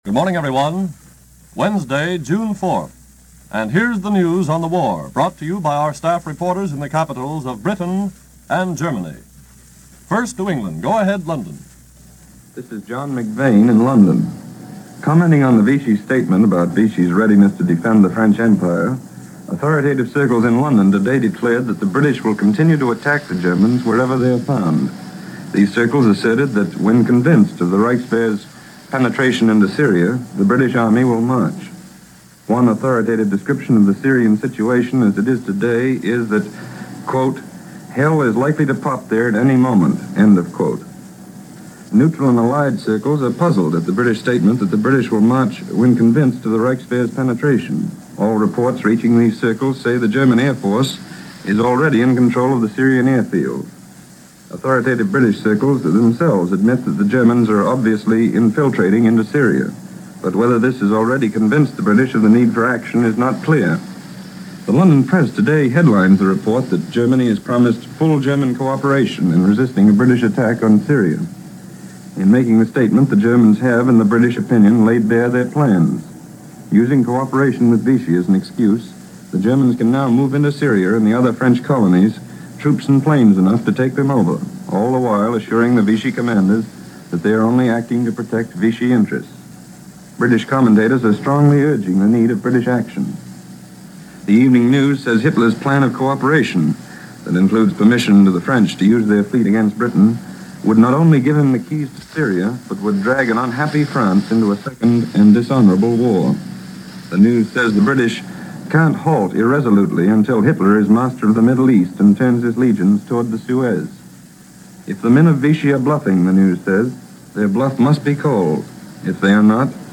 News on War fronts this day in 1941.